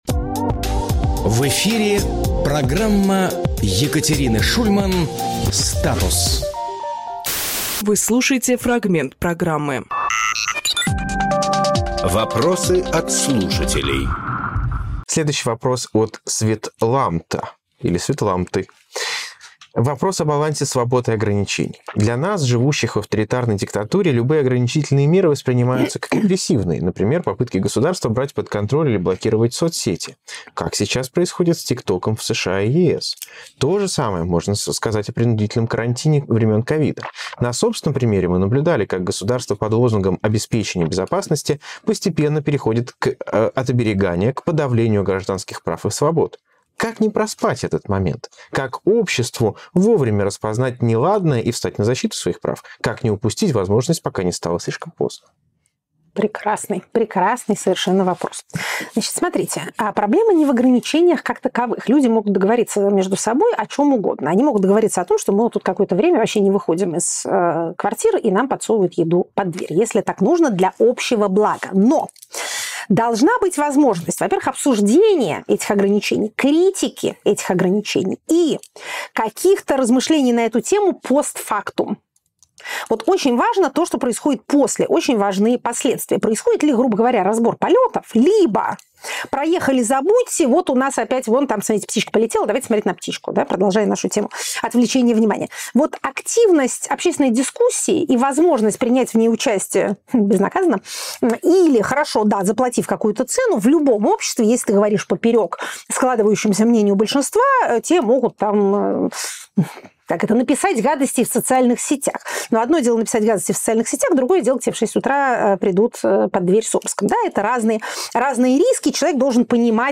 Екатерина Шульманполитолог
Фрагмент эфира от 30.04.24